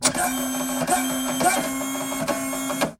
Printer Running
A laser printer warming up and printing pages with paper feed and mechanical cycling
printer-running.mp3